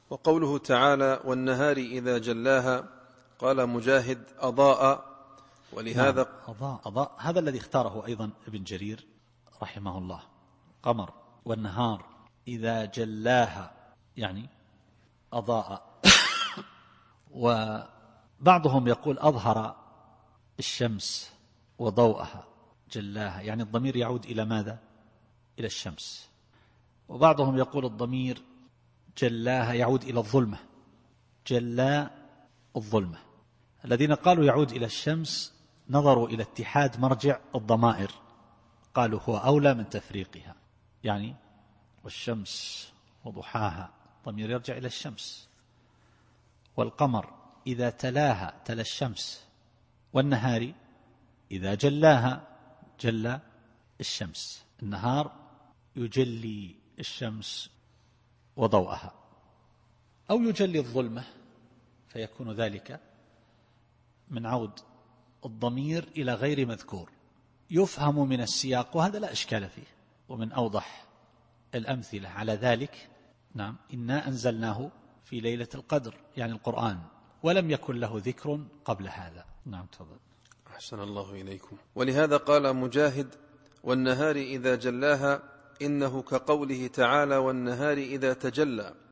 التفسير الصوتي [الشمس / 3]